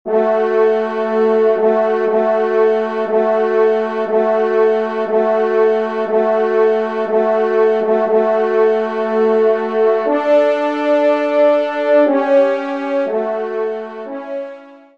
Genre :  Musique Religieuse pour Trois Trompes ou Cors
Pupitre 3° Trompe